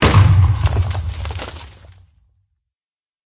explode4.ogg